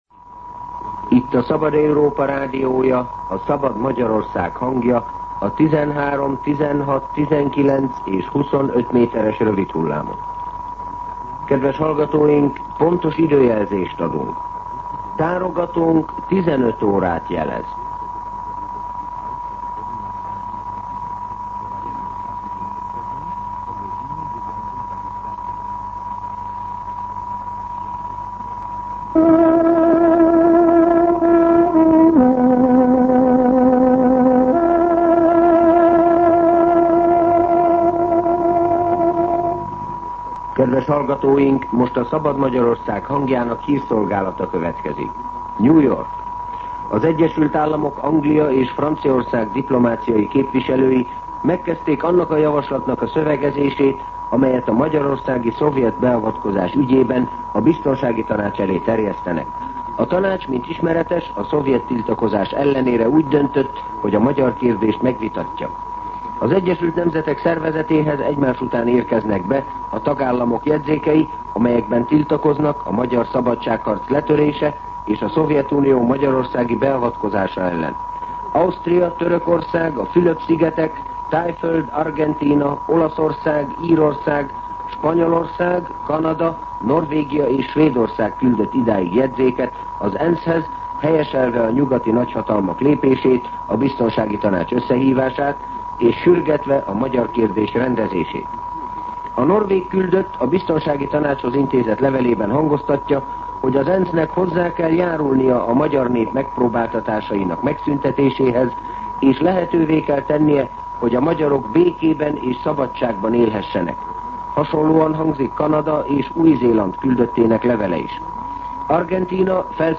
15:00 óra. hírszolgálat